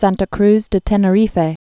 (săntə krz də tĕnə-rēfā, -rēf, -rĭf, säntä krth thĕ tĕnĕ-rēfĕ)